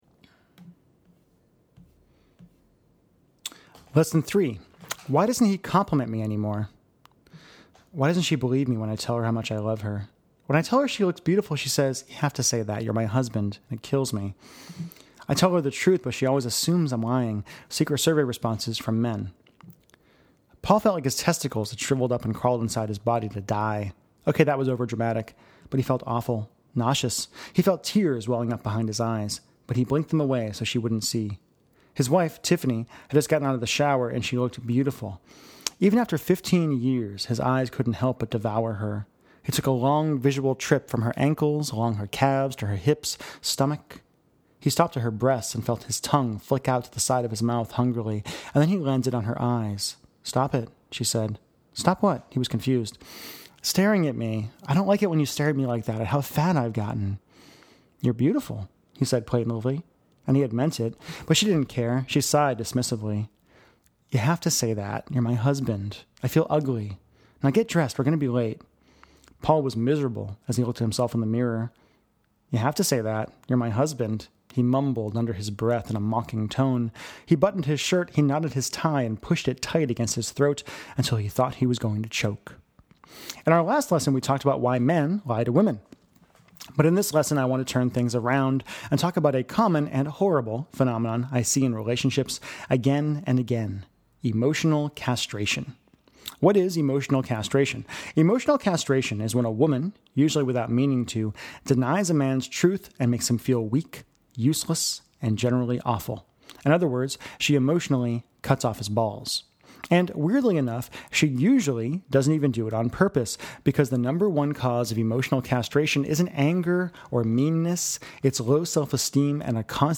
reading the lesson below: